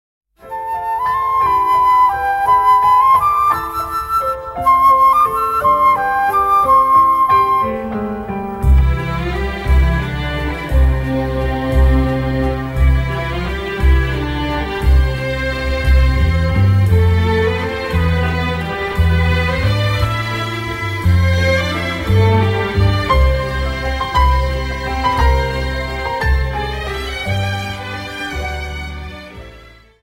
Viennese Waltz Song